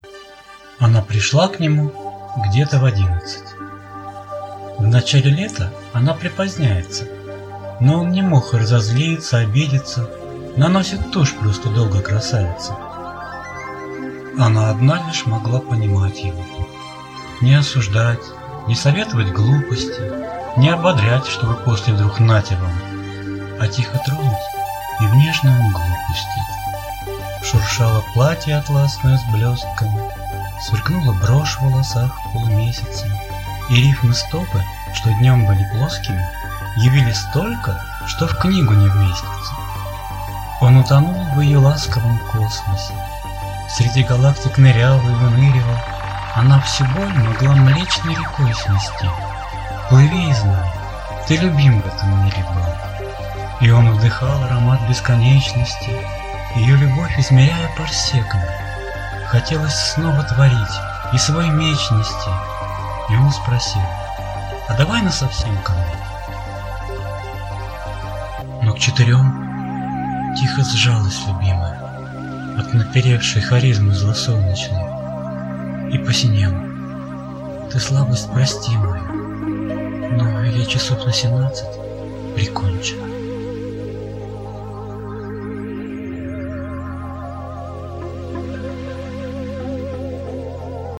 Прослушать в авторском исполнении: